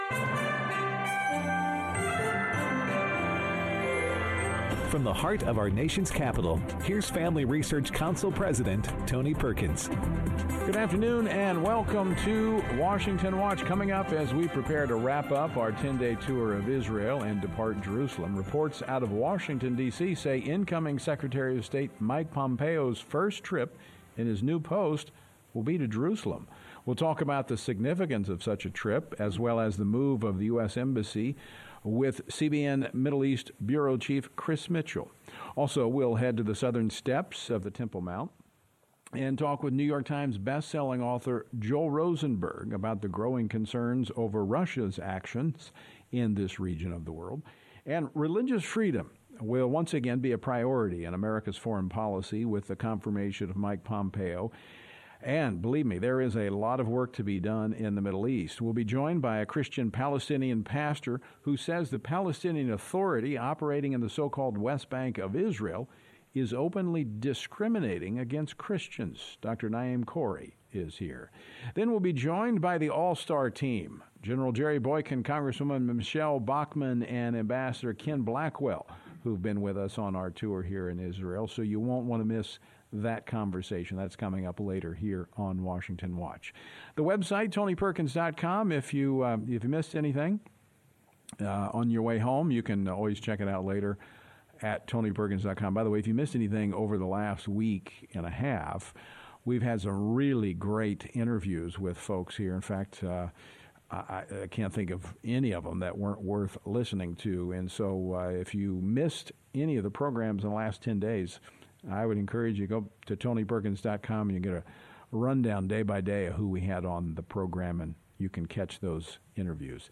From the Southern Steps of Jerusalem, Tony is joined by New York Times best-selling author and teacher, Joel Rosenberg as he highlights the unfolding biblical prophecy in Israel and details the greatest threats to Israel’s security.